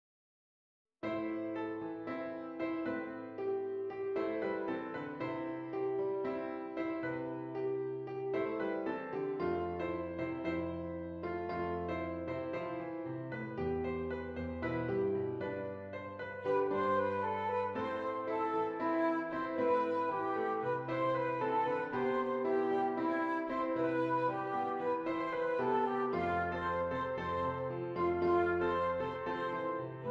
Flute Solo with Piano Accompaniment
A Minor
Moderate